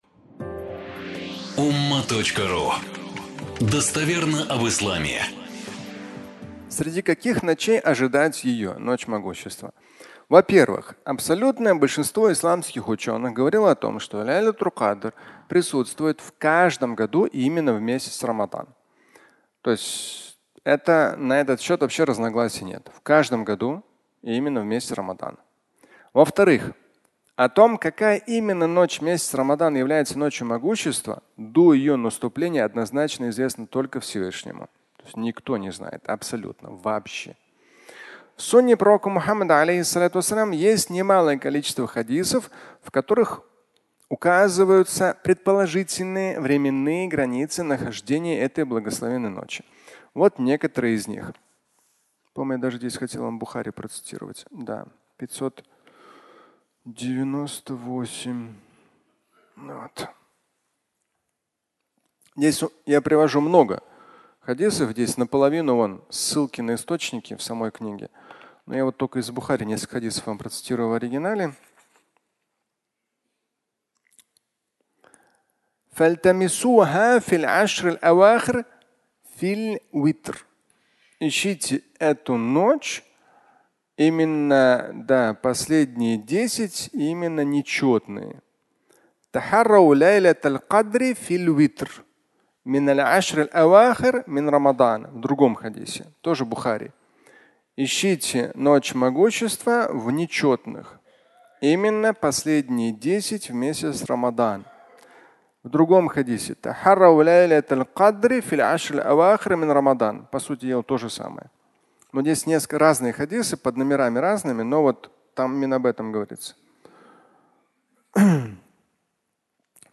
(аудиолекция)